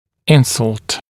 [‘ɪnsʌlt][‘инсалт]поражение, повреждение, травма